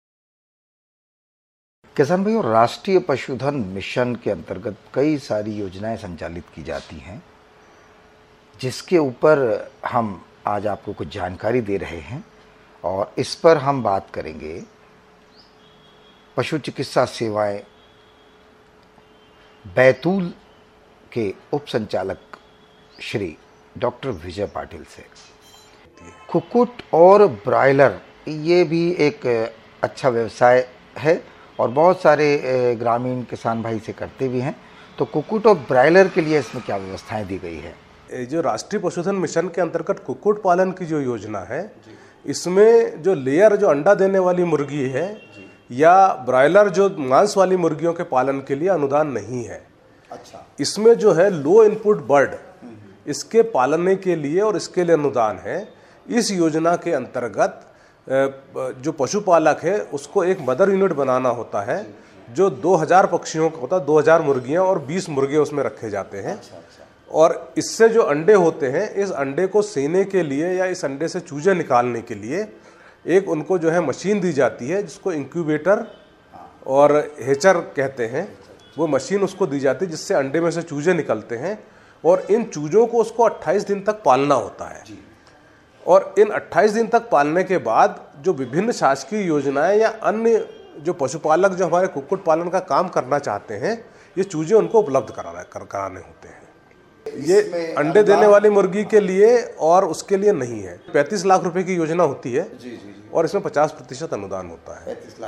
राष्ट्रीय पशुधन मिशन योजना अंतर्गत कैसे लगा सकते हैं मुर्गी फार्म कितने की है सब्सिडी कैसे मिलेगा बैंक से लोन सुनिए बातचीत